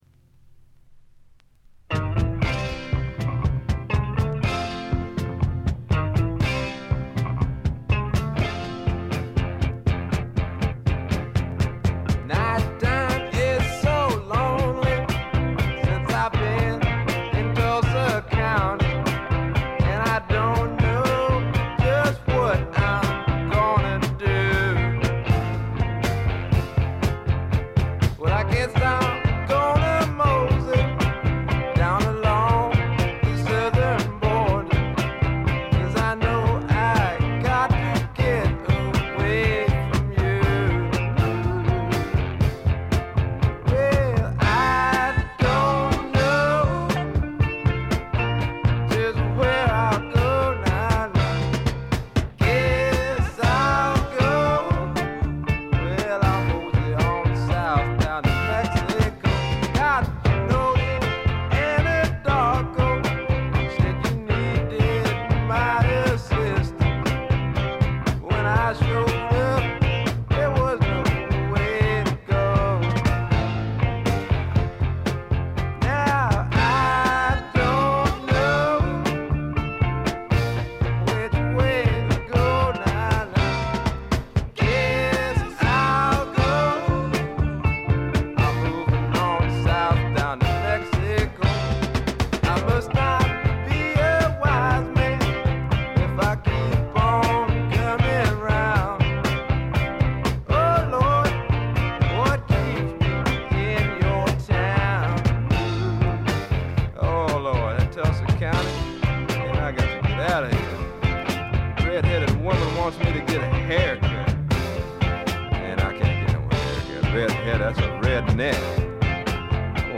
わずかに軽微なチリプチ。
まさしくスワンプロックの真骨頂。
試聴曲は現品からの取り込み音源です。
Vocal, Guitar, Keyboards